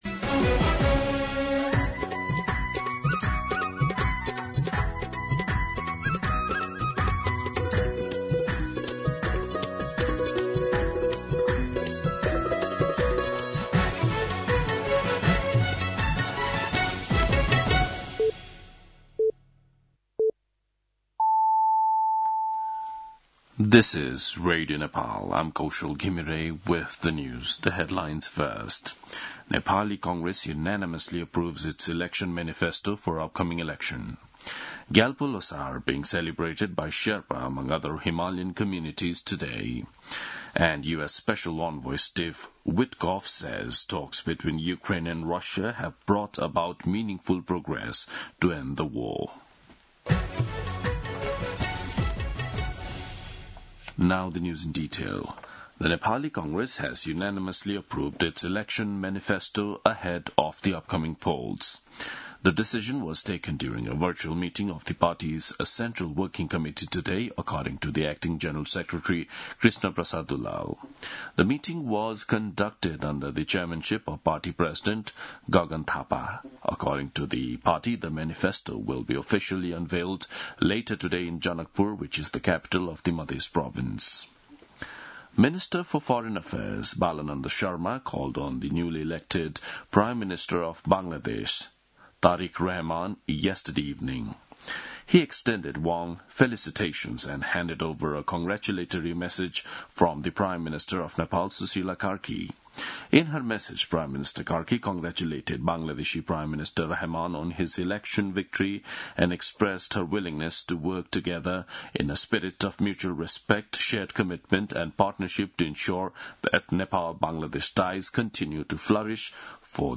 दिउँसो २ बजेको अङ्ग्रेजी समाचार : ६ फागुन , २०८२
2pm-English-News.mp3